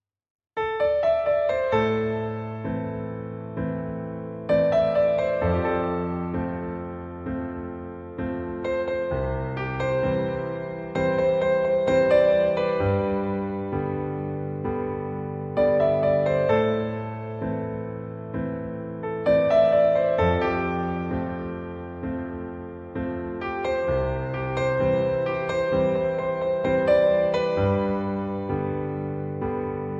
• Key: A Minor
• Instruments: Piano solo
• Genre: Pop